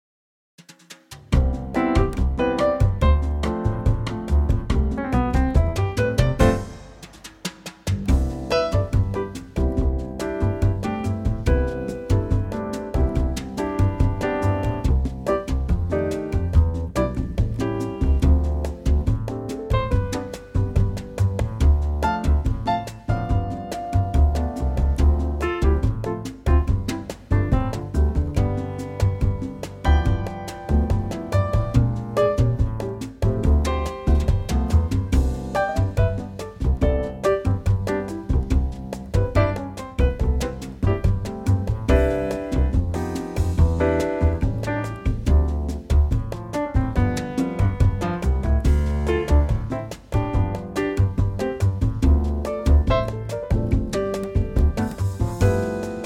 key - C - vocal range - B to E
Trio arrangement
same tempo, form and a few similar lines, but in a male key.